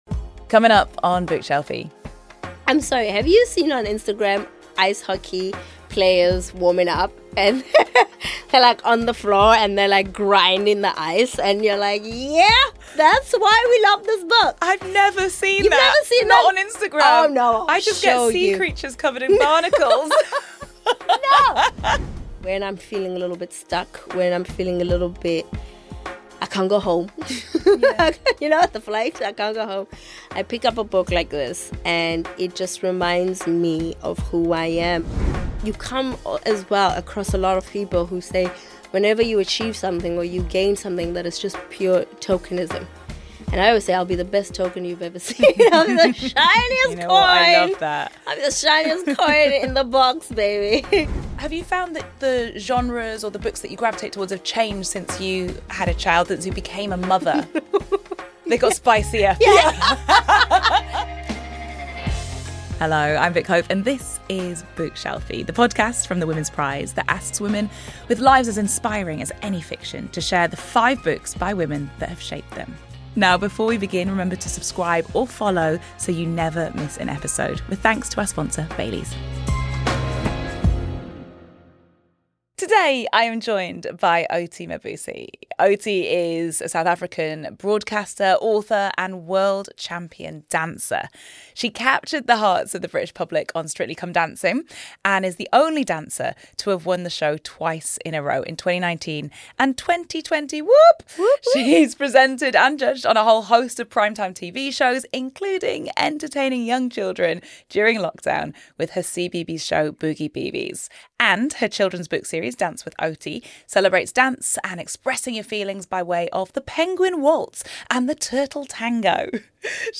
South African broadcaster, author, world-champion dancer and star of Strictly, Oti Mabuse tells Vick about her love of spicy romance novels, her rejection of tokenism and why she loves audiobooks.